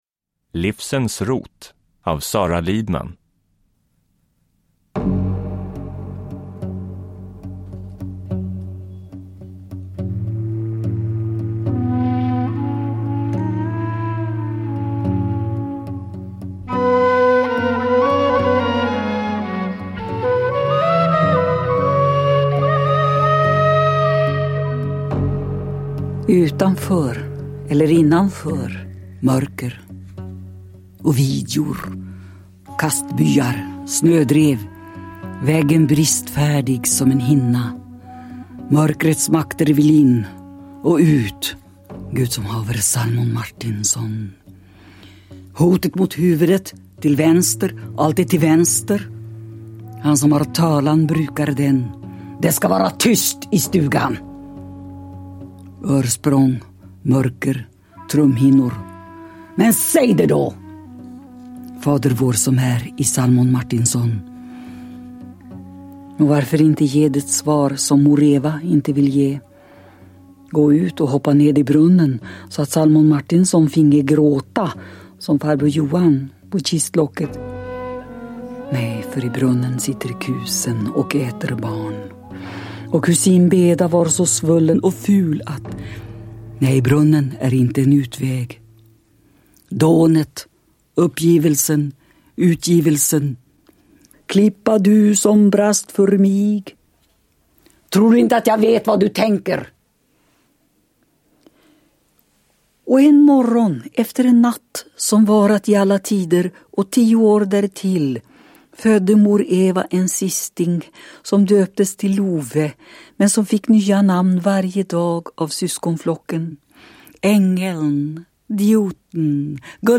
Uppläsare: Sara Lidman
Ljudbok
Sara Lidman själv läser sin bok.